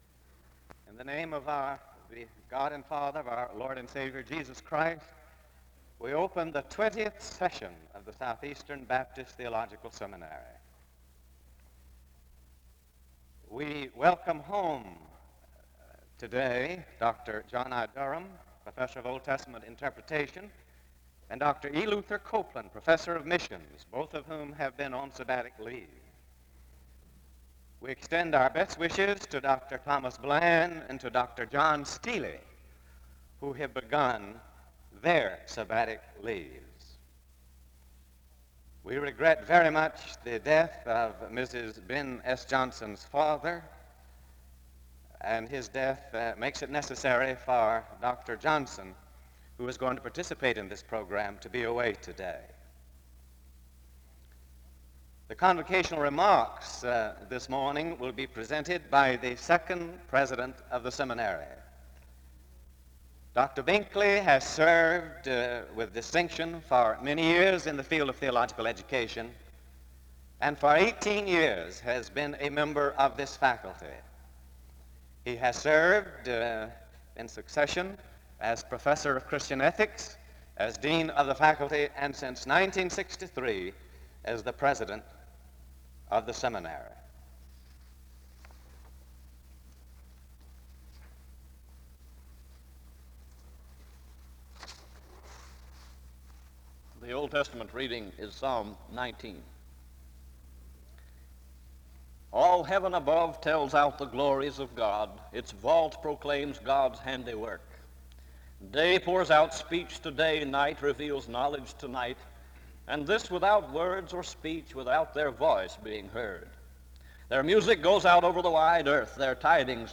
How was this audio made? Location Wake Forest (N.C.) SEBTS Chapel and Special Event Recordings